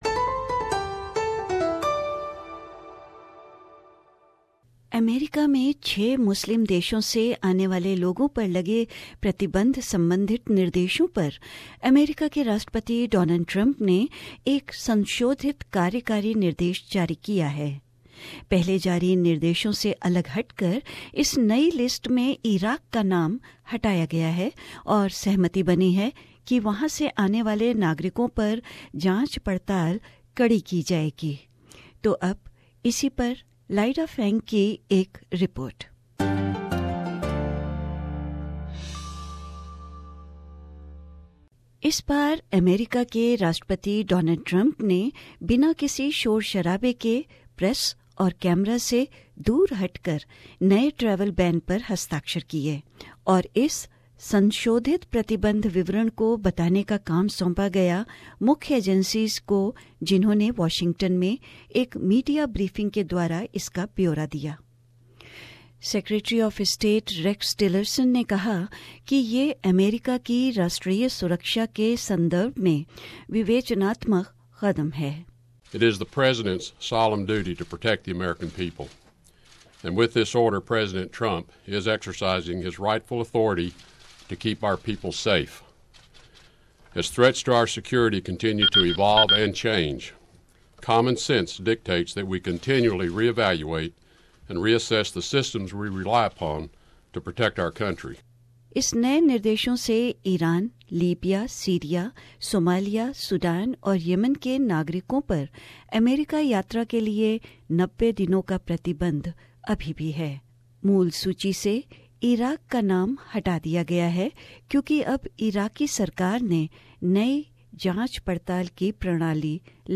A report